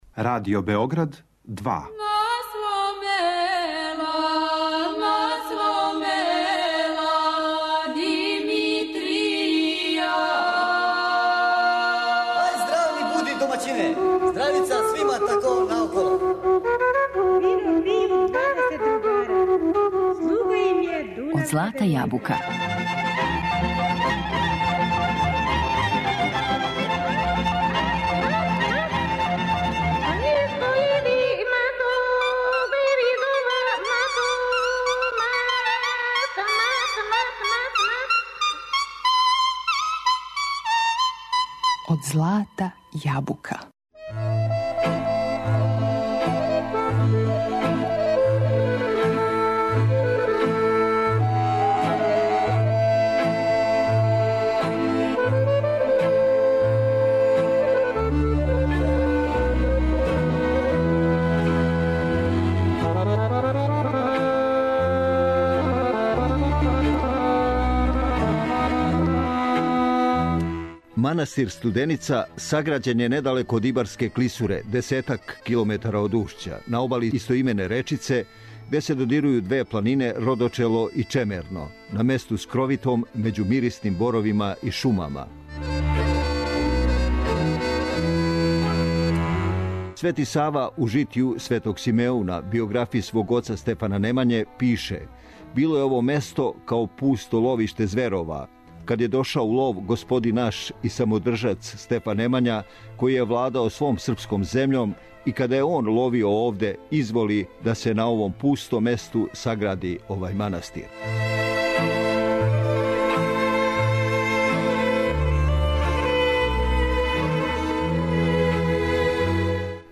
Емисија резервисана за изворну народну музику